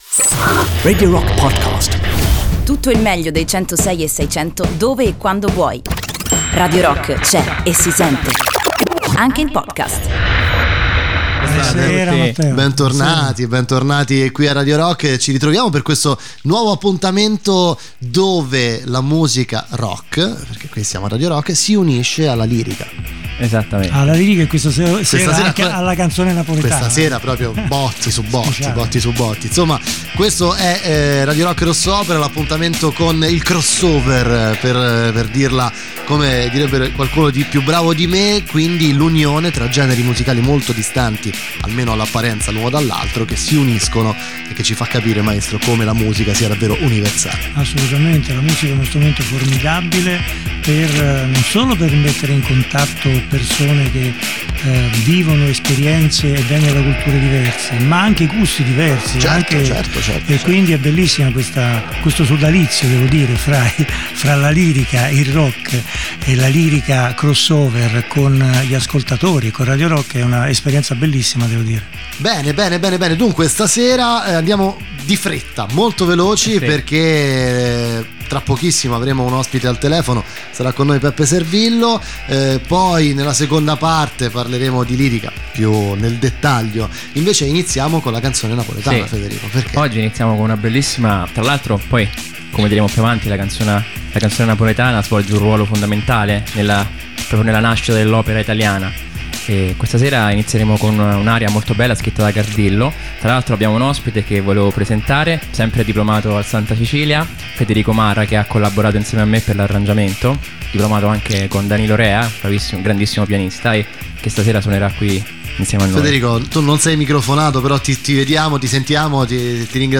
Appuntamento a martedì alternati (a partire dal 3 Aprile 2018) dalle ore 20.00 alle 21.00. In collegamento telefonico Peppe Servillo.